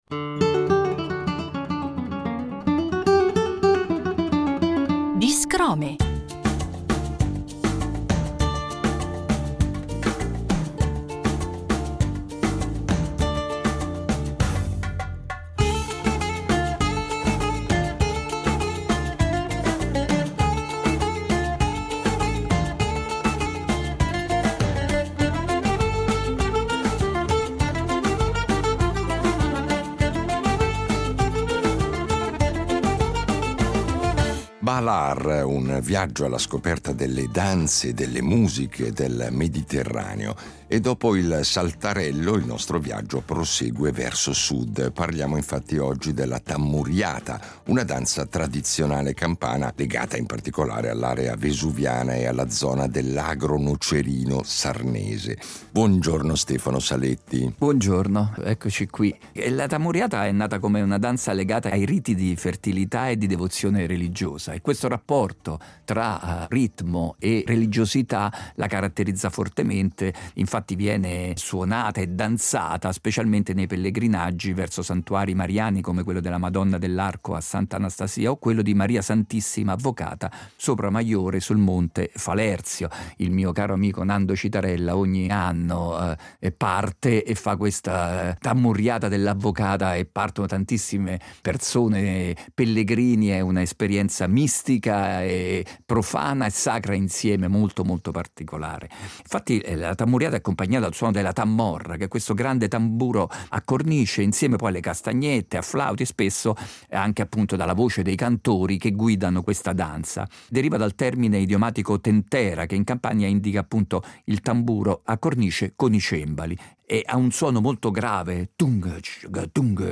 tarantella italiana
Italia-Tammuriata